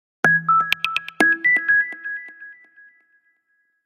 Index of /phonetones/unzipped/Nokia/3610/Alert tones
Message 4.aac